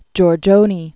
(jôr-jōnē, -nĕ) Originally Giorgio Barbarelli.